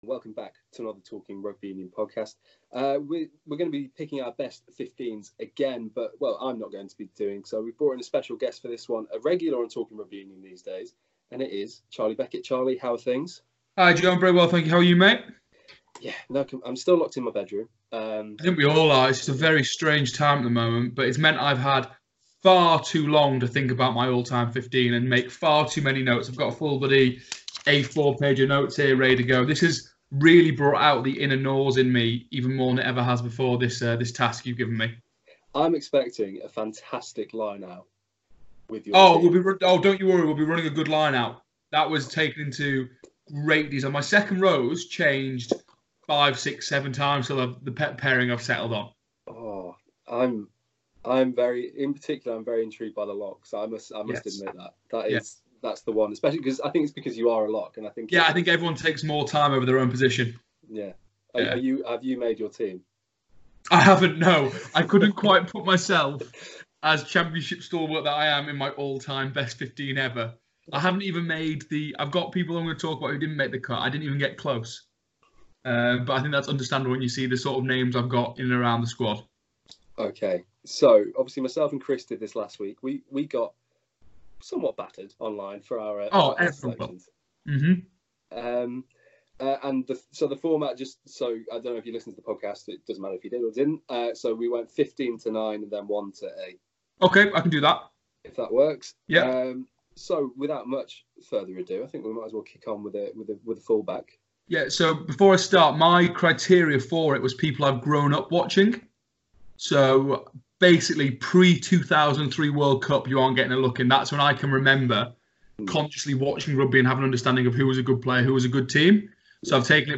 a Skype call